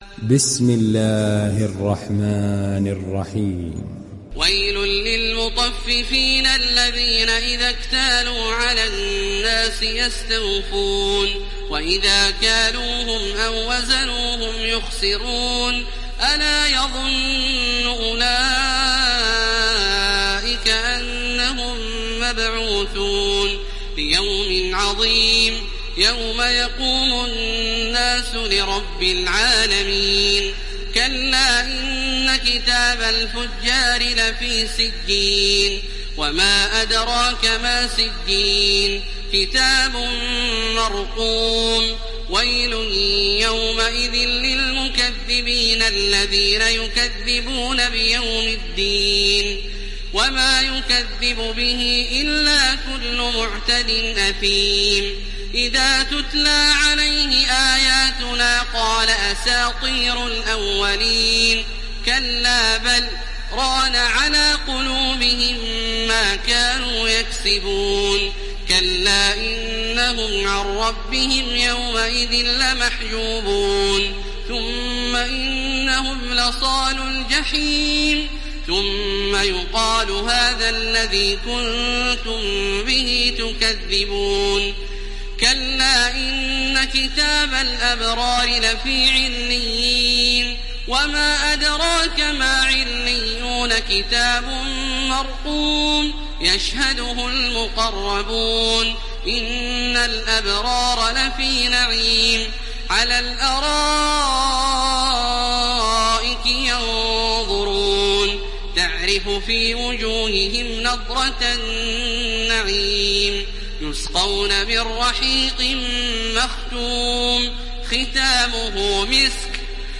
Müteffifin Suresi İndir mp3 Taraweeh Makkah 1430 Riwayat Hafs an Asim, Kurani indirin ve mp3 tam doğrudan bağlantılar dinle
İndir Müteffifin Suresi Taraweeh Makkah 1430